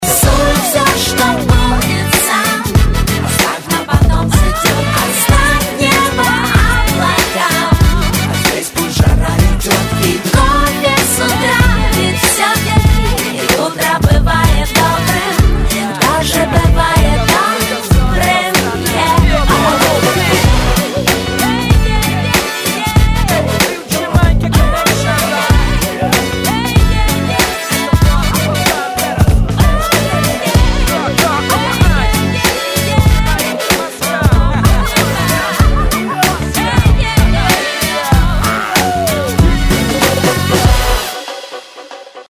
Категория: Rap, RnB, Hip-Hop